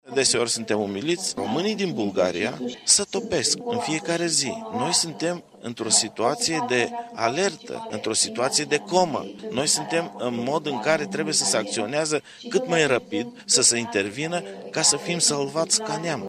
Şi românii din afara graniţelor au nevoie de o strategie coerentă pentru salvarea identităţii naţionale şi a credinţei ortodoxe, s-a subliniat la Universitatea de la Izvorul Mureşului.